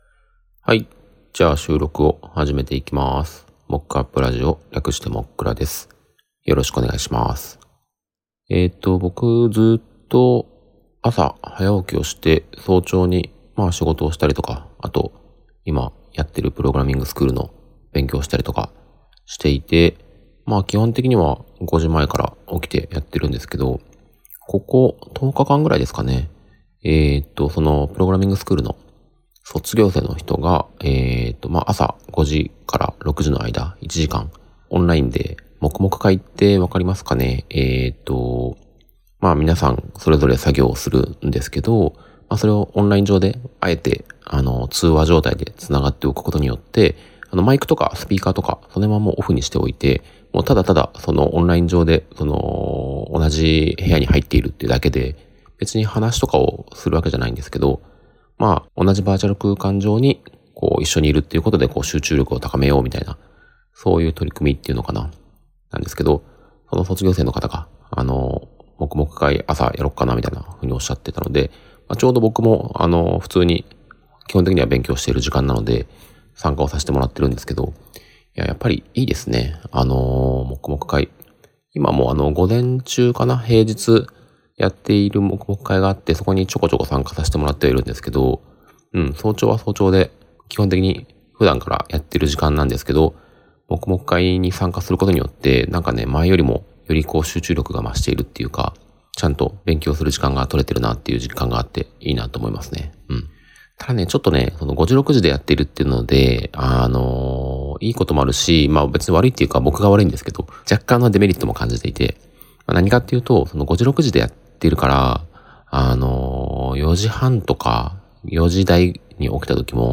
Audio Channels: 2 (stereo)